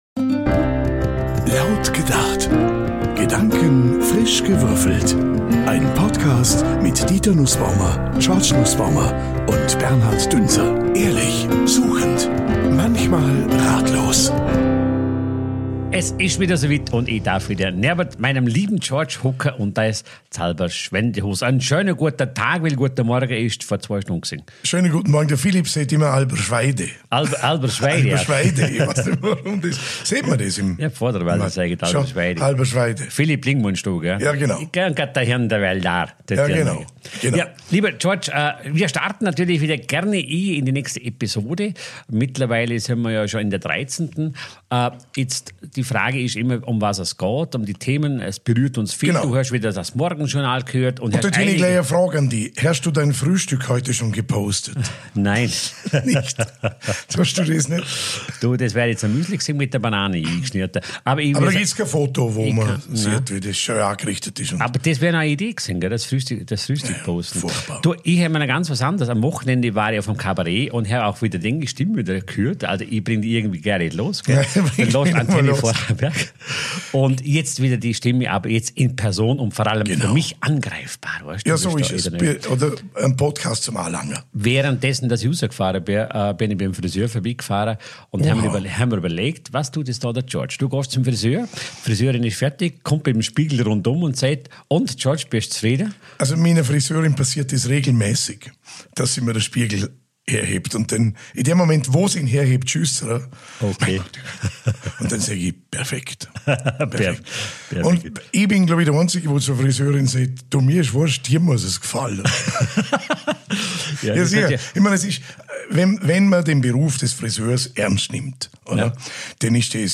Dieses Mal stehen zur Auswahl: Was machen die Algorithmen mit uns? Oder: Wer überwacht uns? Was folgt, ist ein ehrliches, spontanes Gespräch: mal tiefgründig, mal leicht, mal überraschend anders.
Laut gedacht ist ein Podcast ohne Drehbuch, aber mit Haltung.